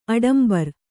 ♪ aḍambar